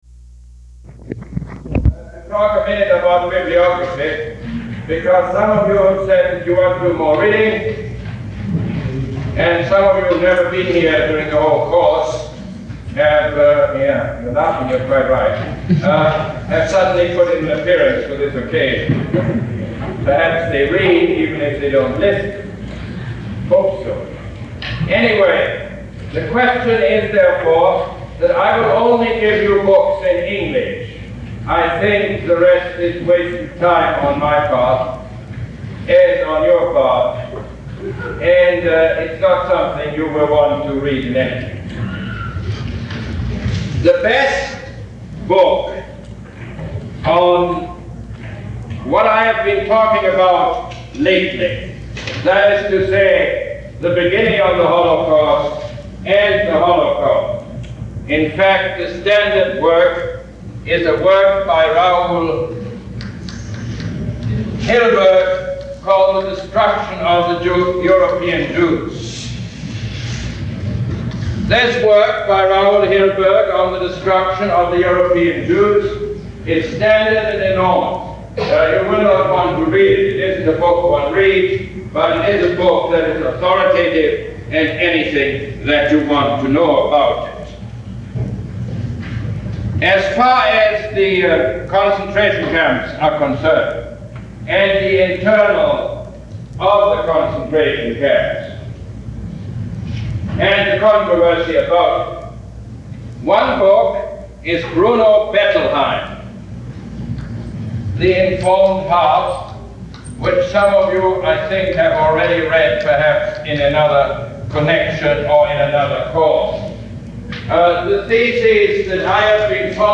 Lecture #28 - May 21, 1971